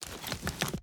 Bow Take Out 1.wav